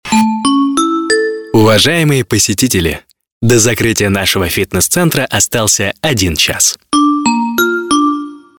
Муж, Другая/Средний